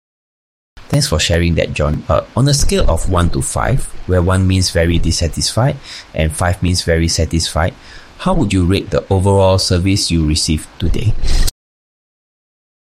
These are sample AI-driven voice interactions, adapted dynamically based on how customers respond
Malaysia | English | AI collecting structured rating data for analytics and reporting